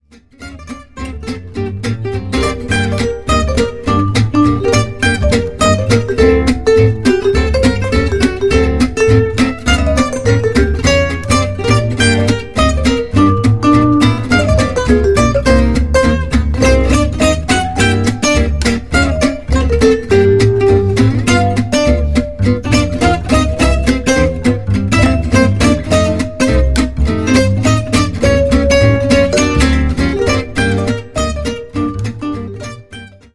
jazz manouche